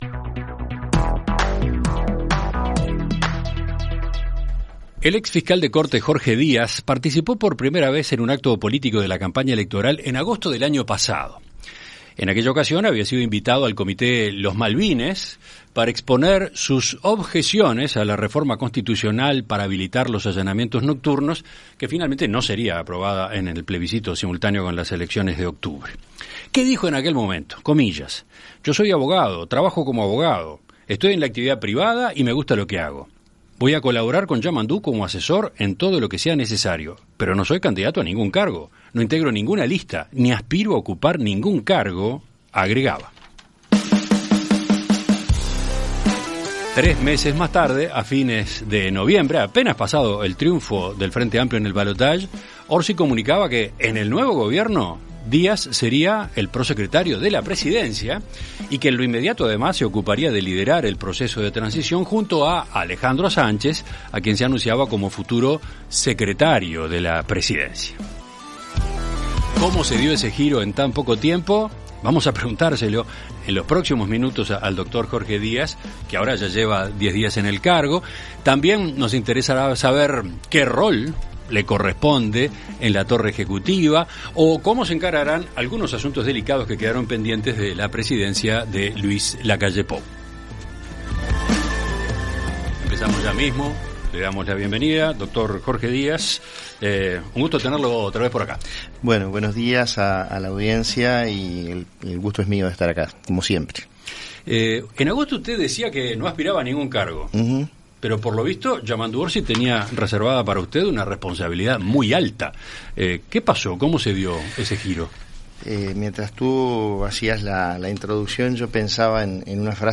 En Perspectiva Zona 1 – Entrevista Central: Jorge Díaz - Océano
Conversamos con el prosecretario de Presidencia, Jorge Díaz.